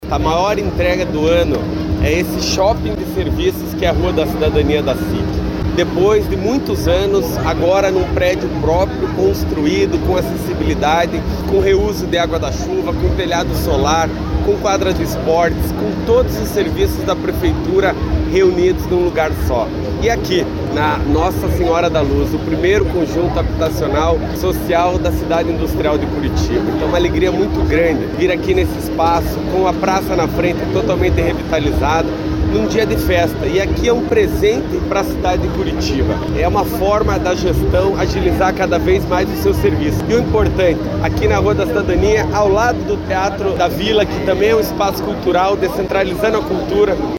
O prefeito Eduardo Pimentel participou da inauguração e afirmou que os serviços oferecidos na Rua da Cidadania vão facilitar a vida dos moradores da região.